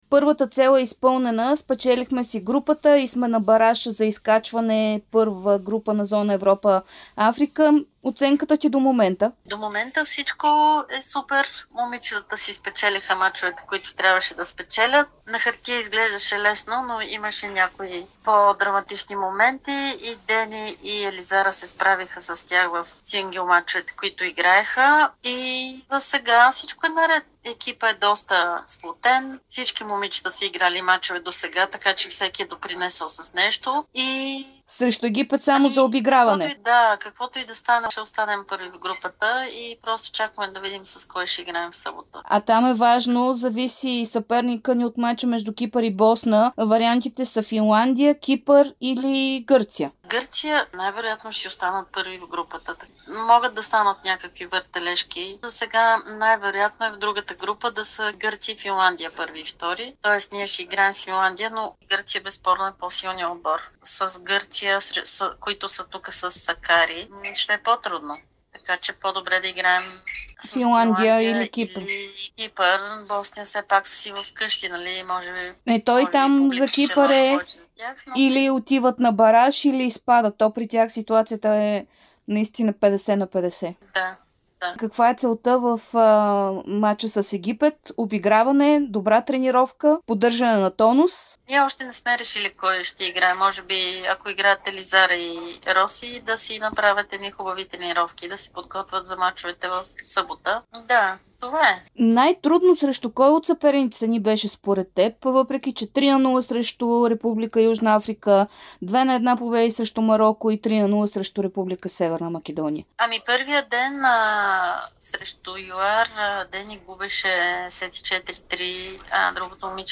Капитанът на България за "Били Джийн Кинг Къп" Маги Малеева даде интервю за Дарик и Dsport от Баня Лука. България изпълни първата си цел - спечели групата си и в събота ще играе бараж за изкачване в Първа група зона Европа - Африка на световното отборно първенство по тенис за жени.